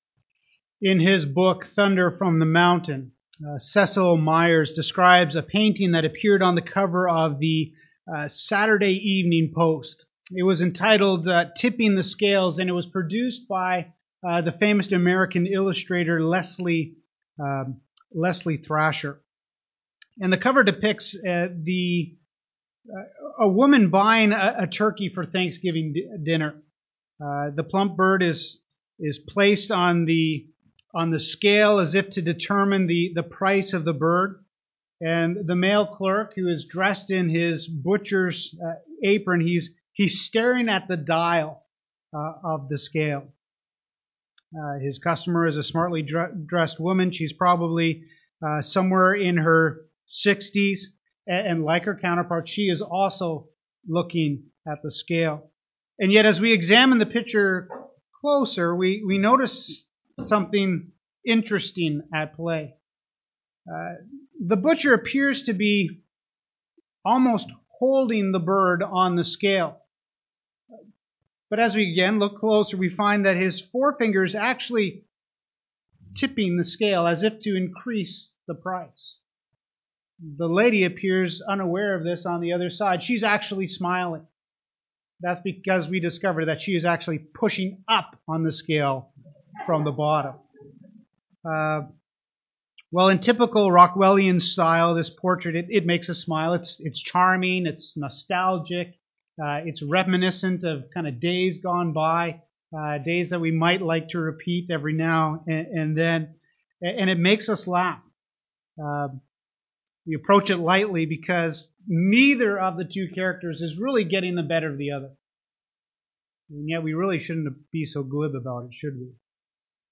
10 Commandment Sermon Series